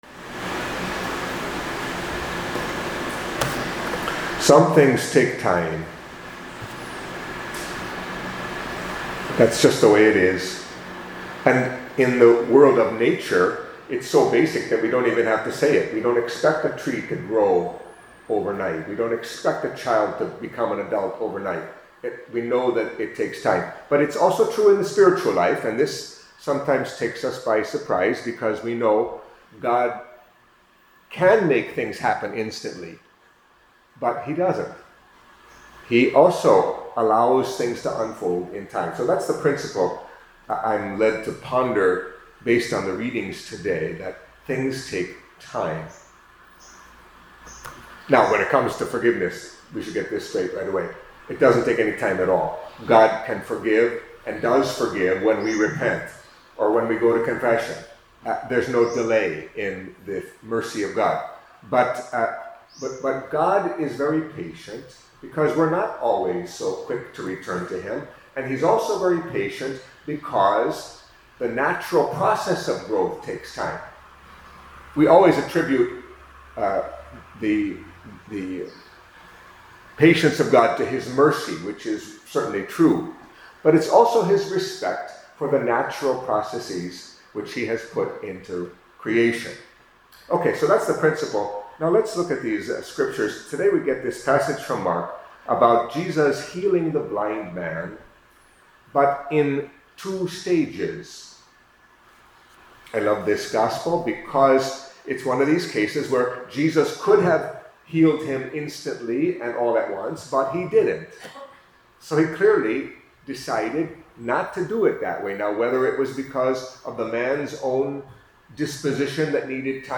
Catholic Mass homily for Wednesday of the Sixth Week in Ordinary Time